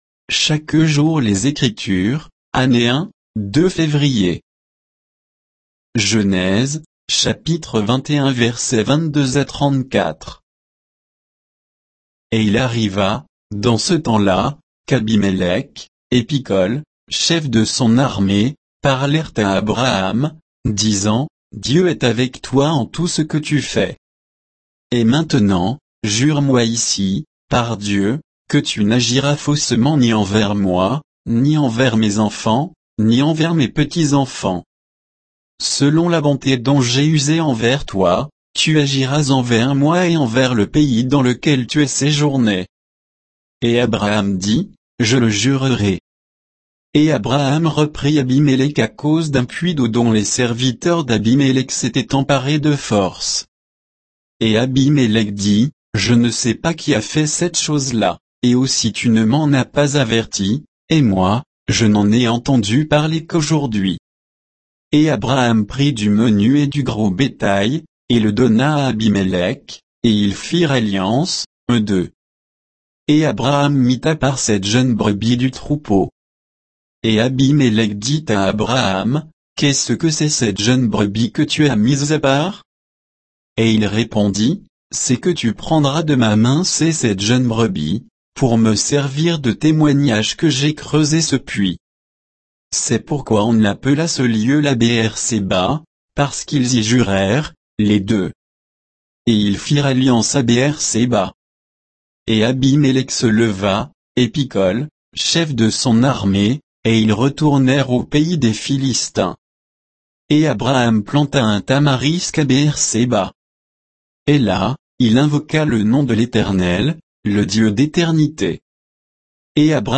Méditation quoditienne de Chaque jour les Écritures sur Genèse 21